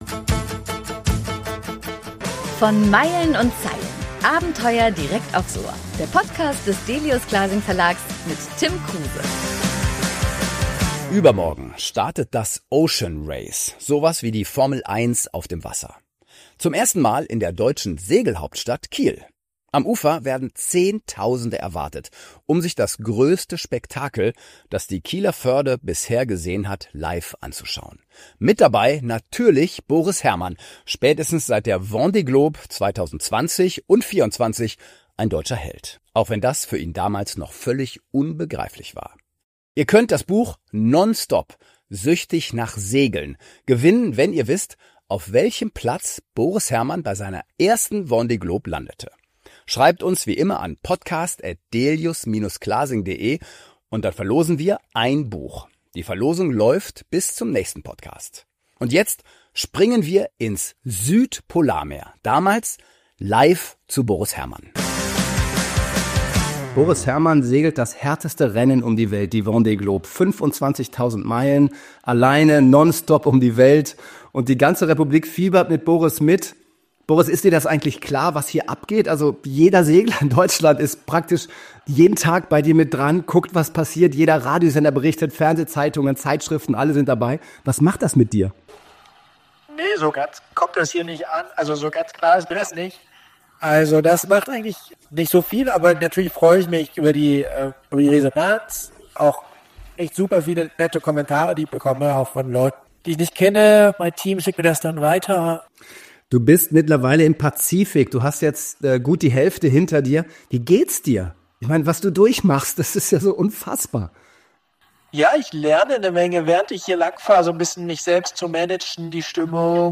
Special - Boris Herrmann Live-Interview vom 20.12.2020